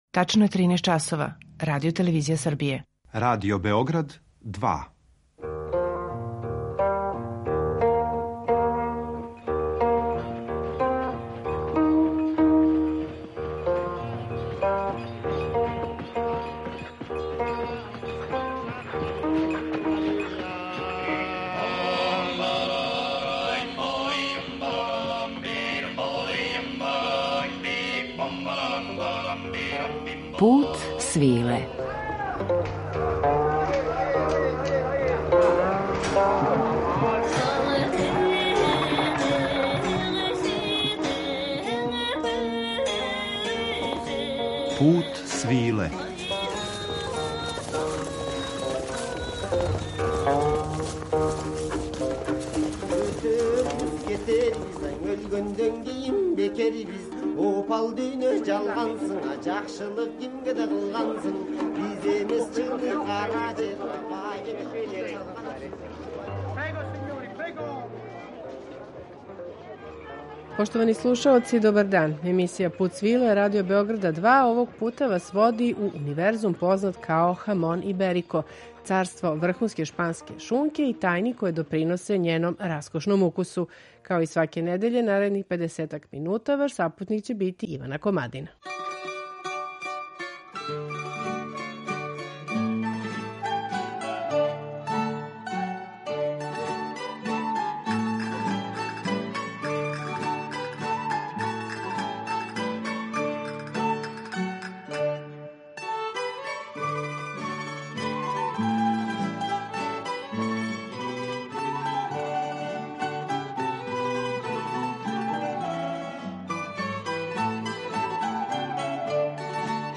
Данашњи Пут свиле води у тајни свет најбоље шпанске пршуте, уз музику из руралних области Шпаније: Ла Манче, Галиције, Андалузије, Вера, села у близини Тарифе, Гвадалахаре, Сеговије, Мурсије, Оливенсе.